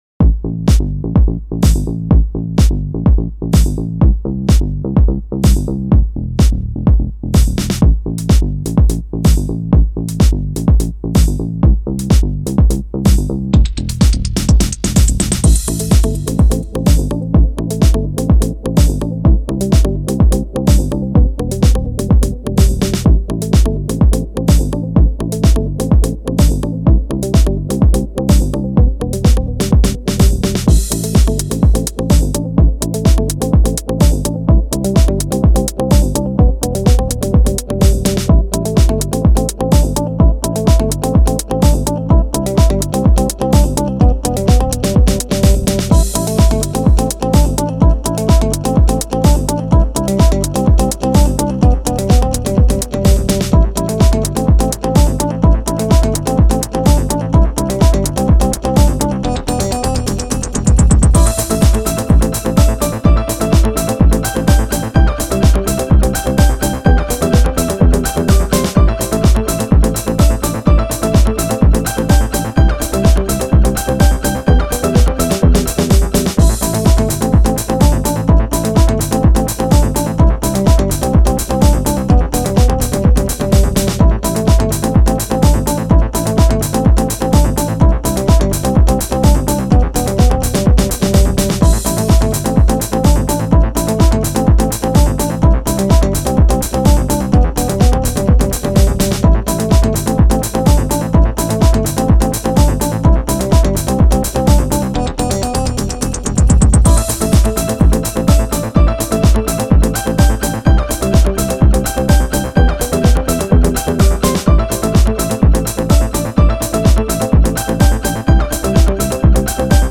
finest electronic music.